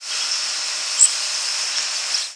Zeep calls
L. Waterthrush
The calls are short, typically between 1/20th and 1/10th of a second (50-100 mS) in duration, and high-pitched, typically between 6-10 kHz. The calls have an audible modulation that gives them a ringing, buzzy, or sometimes trilled quality.